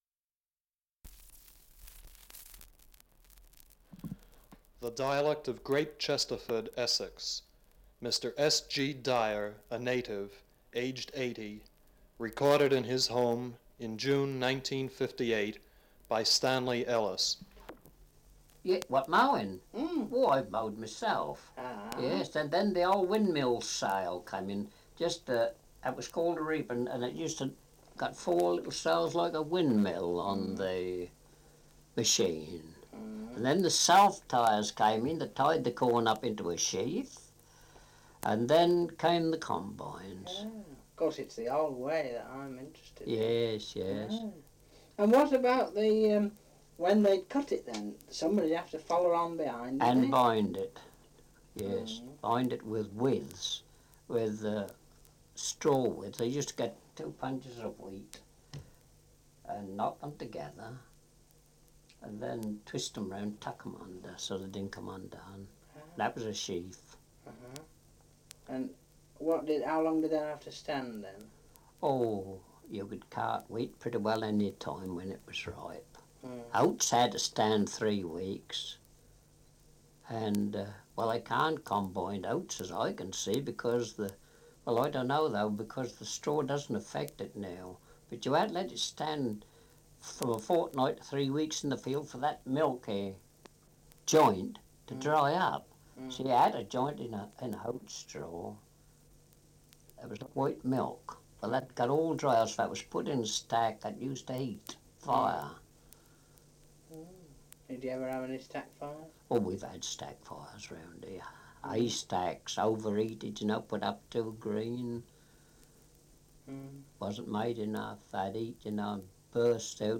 Site Location(s): Place name - Great Chesterford, Uttlesford, Essex, England, United Kingdom( 52.0643, 0.1972 )
1 - Survey of English Dialects recording in Great Chesterford, Essex. Survey of English Dialects recording in Belchamp Walter, Essex
78 r.p.m., cellulose nitrate on aluminium